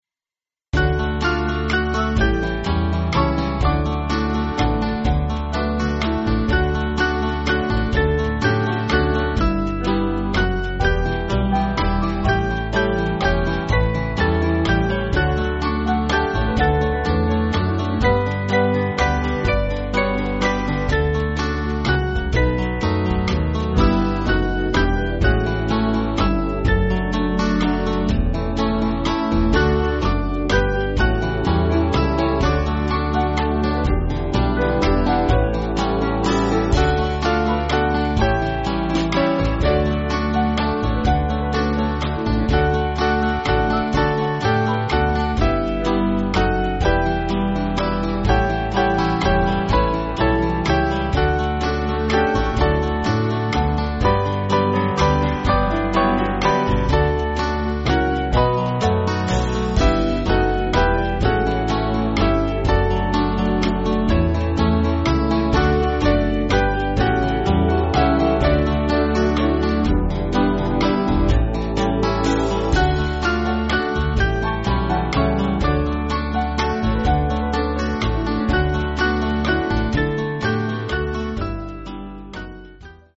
Small Band
(CM)   4/D-Eb